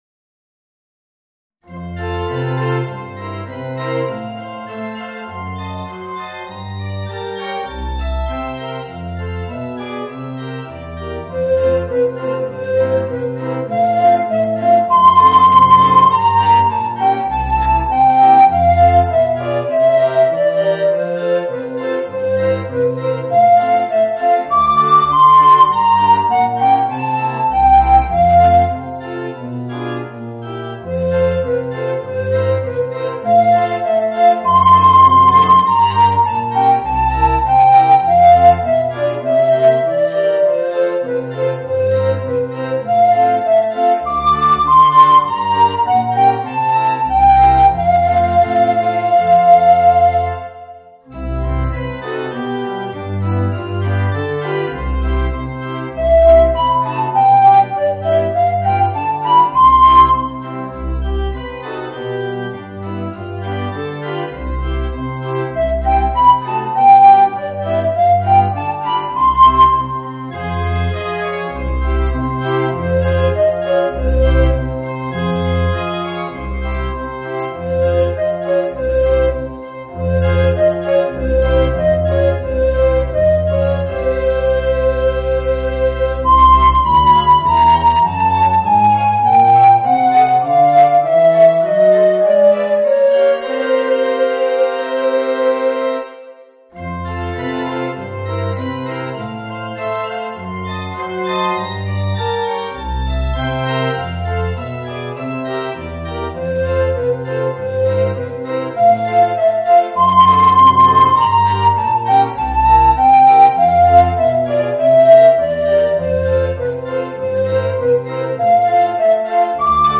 Voicing: Alto Recorder and Organ